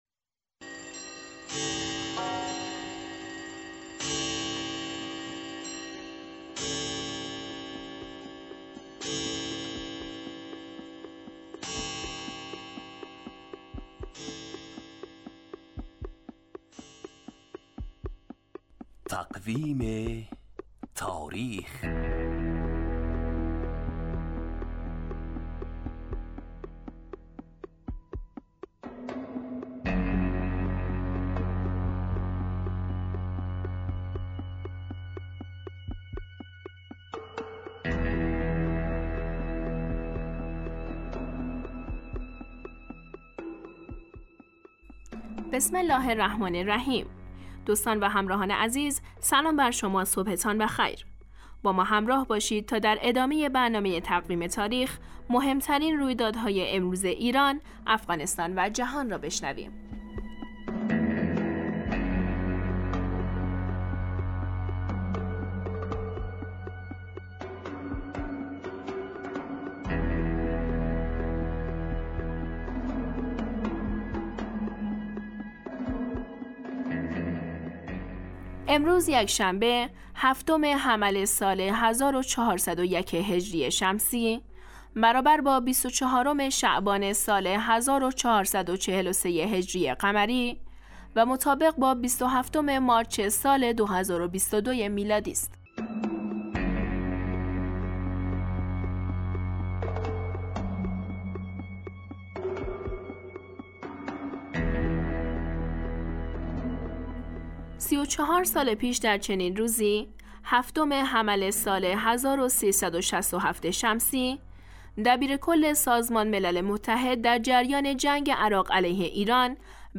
برنامه تقویم تاریخ هرروز ساعت 7:10 دقیقه به وقت افغانستان پخش میشود.